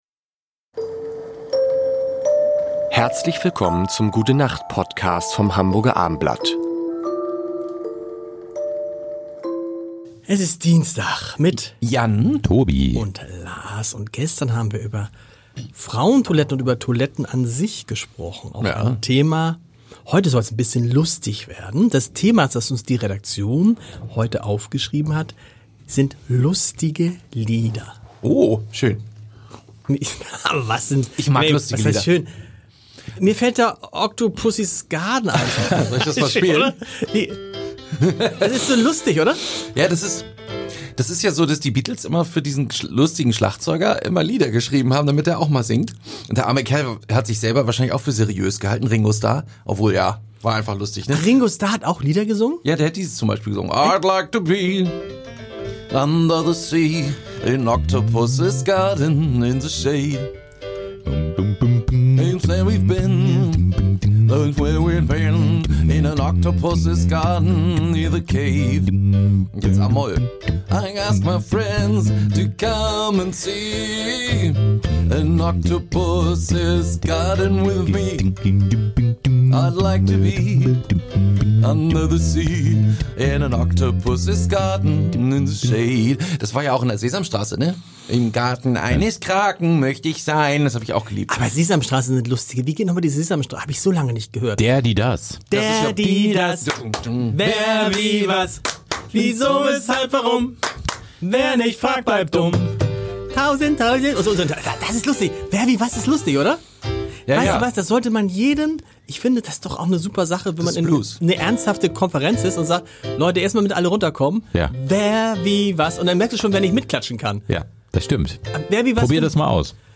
wundervollen Ballade.